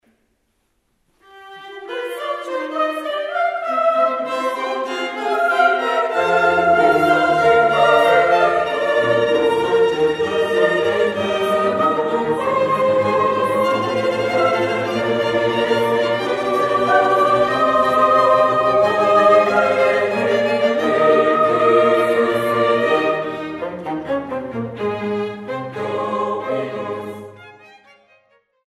per soli, coro femminile, orchestra d'archi e basso continuo